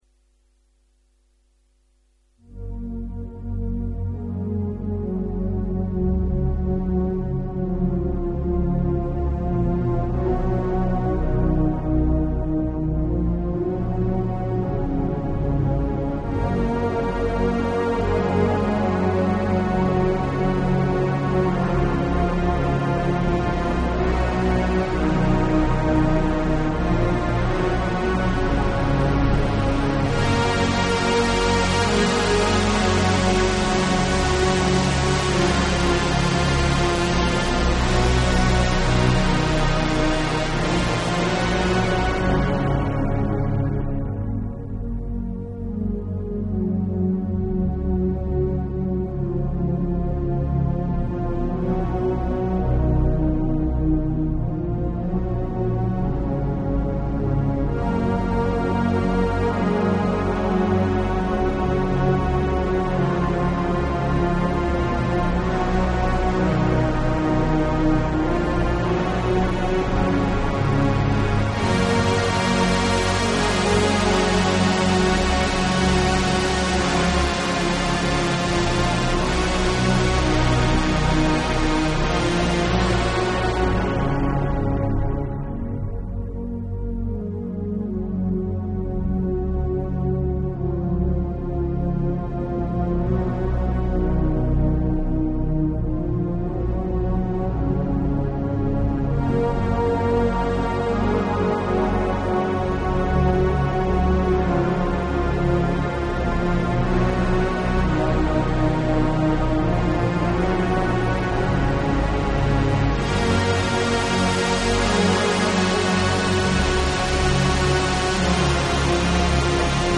Tags: fantasy